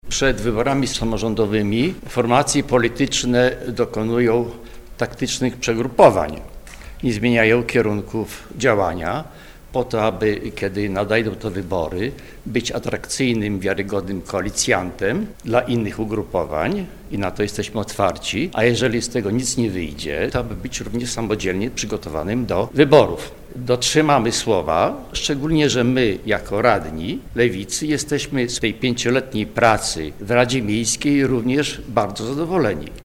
O odejściu z klubu prezydenta mówi także radny Czesław Cyrul.